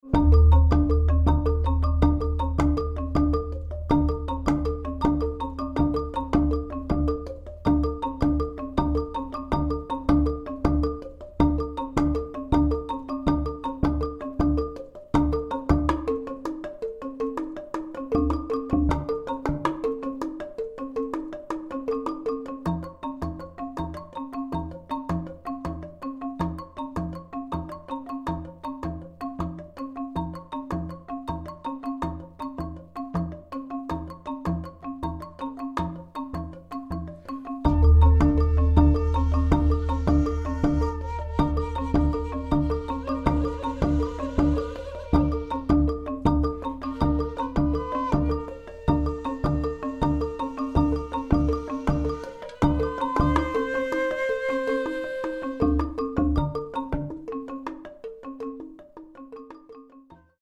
Balinese tingklik
electronics and gong
shakuhachi 4:47